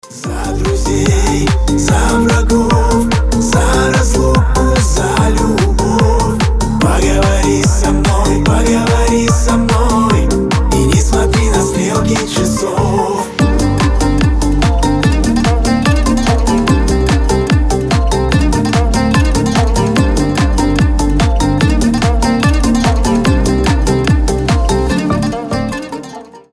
• Качество: 320, Stereo
душевные
восточные